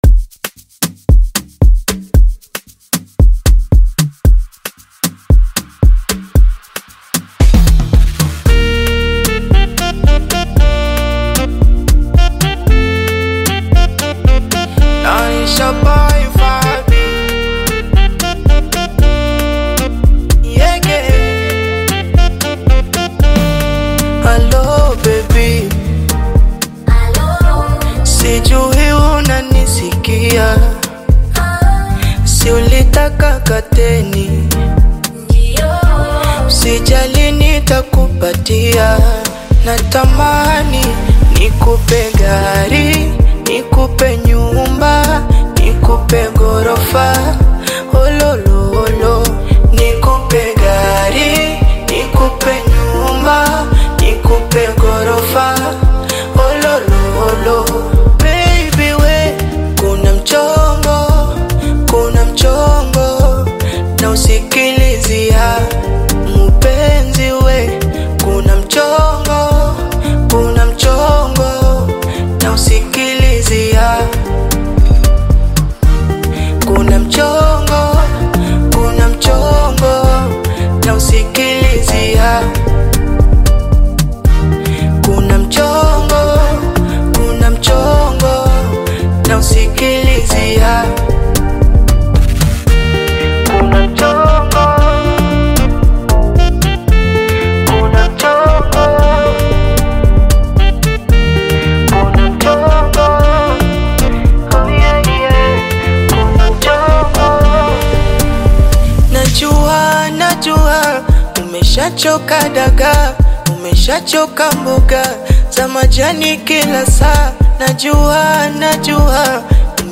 R&B-infused single